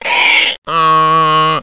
HEEHAW.mp3